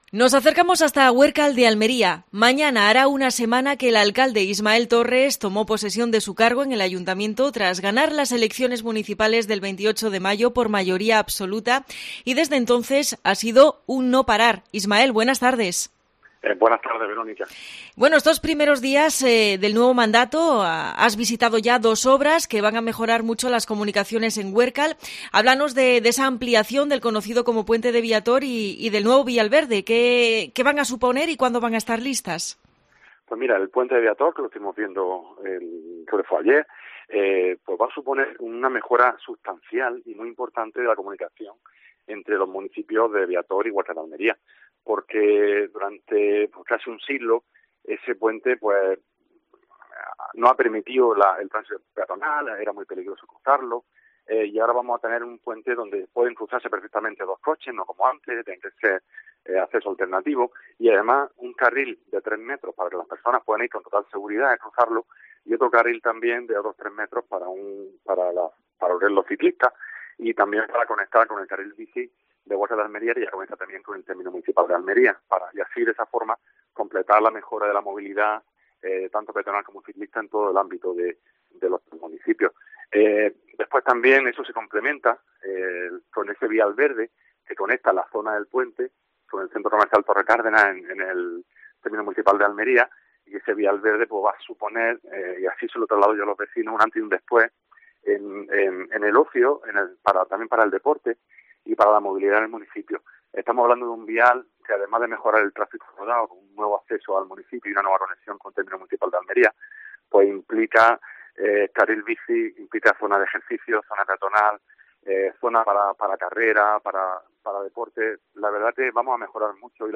Entrevista al alcalde de Huércal de Almería, Ismael Torres